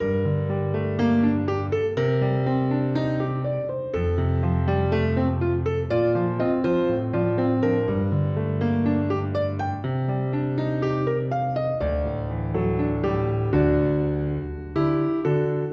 原声钢琴 D小调 122bpm
描述：D小调的原声钢琴循环曲...
Tag: 122 bpm RnB Loops Piano Loops 2.65 MB wav Key : D